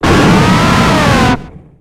Cri de Drakkarmin dans Pokémon X et Y.